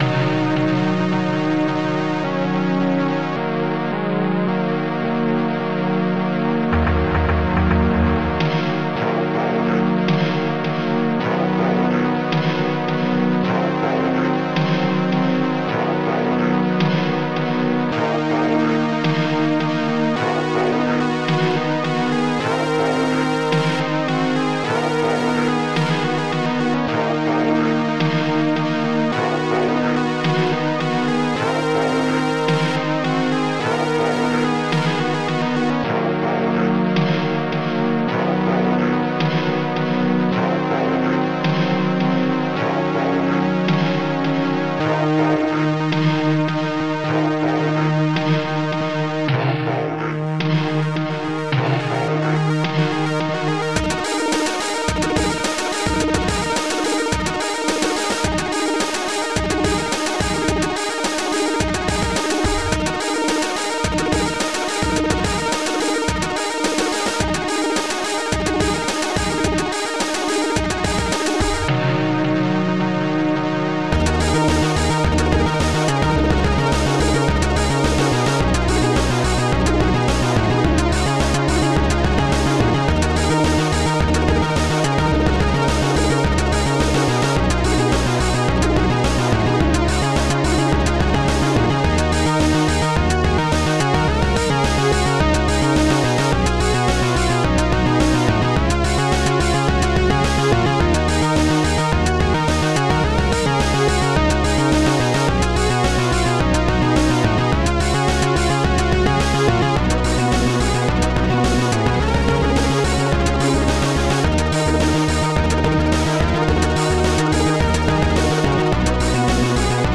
Protracker Module
2 channels
ST-16:-pitchsynth ST-13:PUKA1 ST-16:robotvoice2 ST-04:bassdrum10 ST-17:blsnarefed2 ST-17:blhihat- ST-11:ronkhihat ST-15:bazz